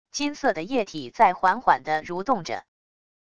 金色的液体在缓缓的蠕动着wav音频生成系统WAV Audio Player